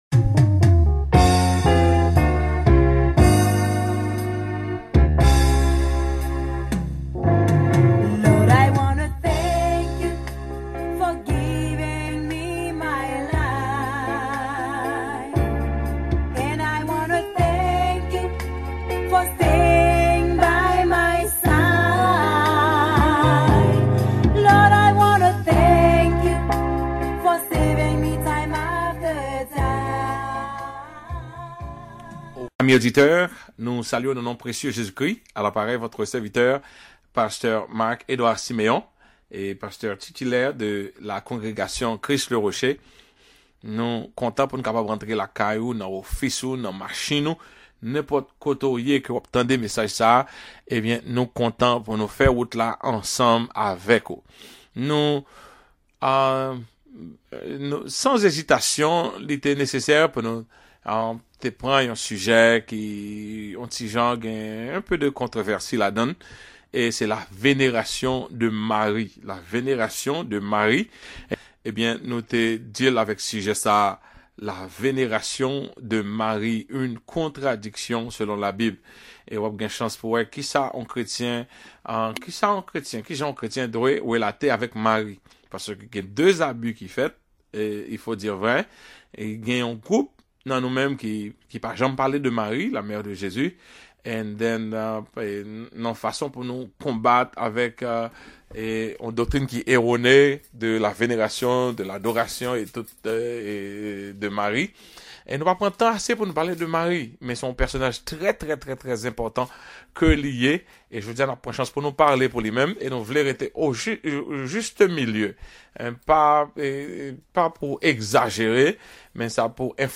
PLEASE CLICK HERE TO DOWNLOAD THE NOTES FOR THE SERMON: L’ADORATION DE MARIE UNE CONTRADICTION SELON LA BIBLE ( )